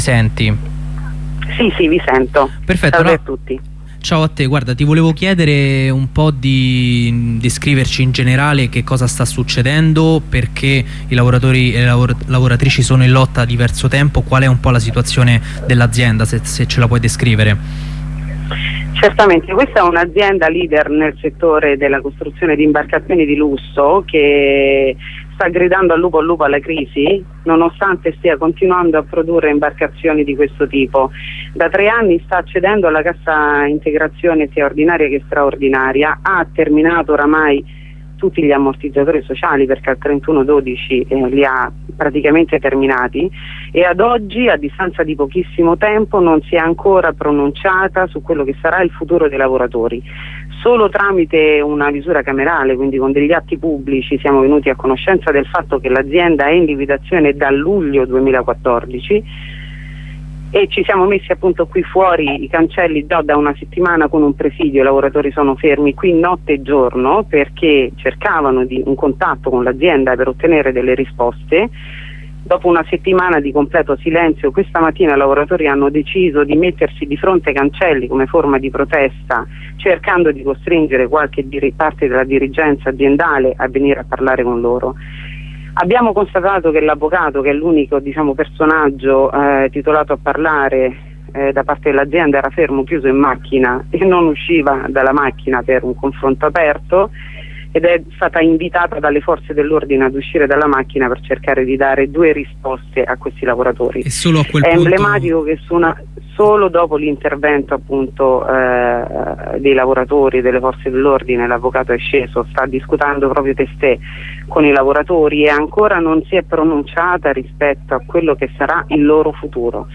Subito dopo la corrispondenza un delegato USI del settore marittimo-fluviale ha espresso piena solidarietà alla lotta dei lavoratori e delle lavoratrici Canados.